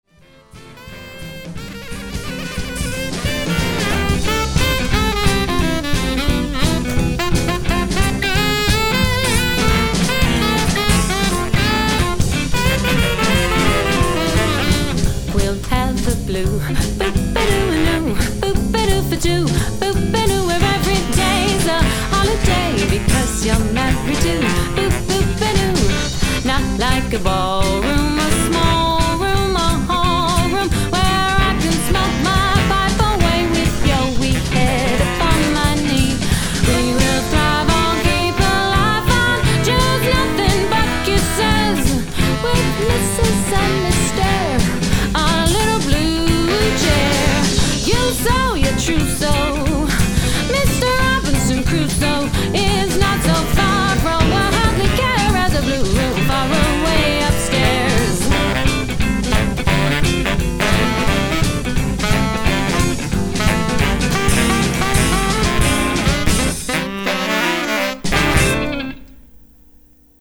1920s Gatsby Era Jazz Band
Swing Jazz Band